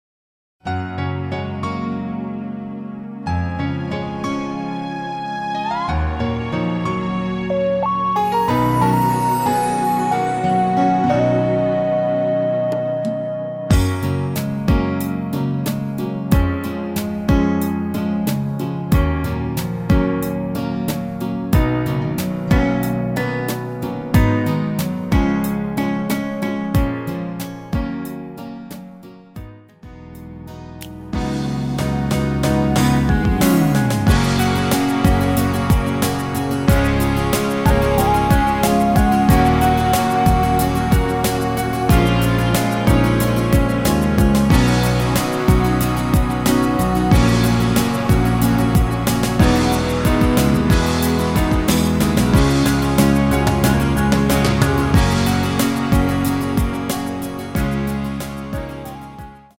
-2)내린 MR 입니다.